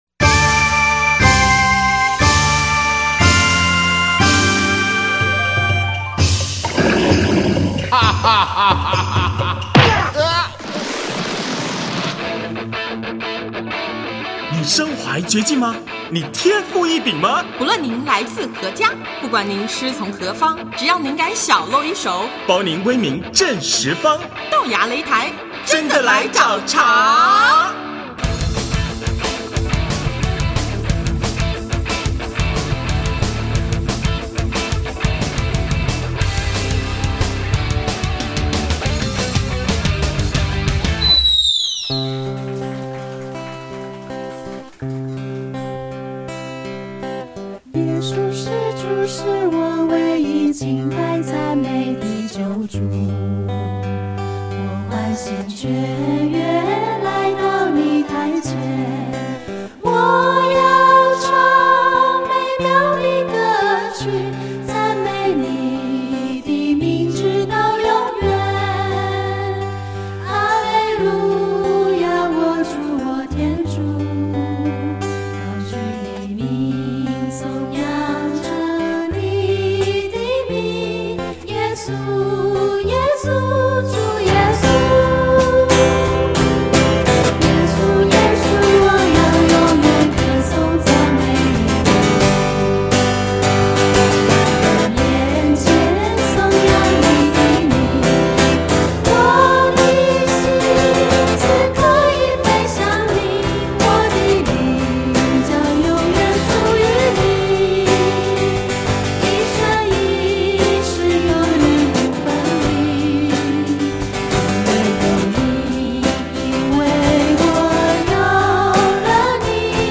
“归于主爱”青年音乐共融分享会2009年4月18日下午在台湾台北天主教圣家堂举行，教会内的青年团体分享歌曲与共融，约五个团体各表演四首曲子。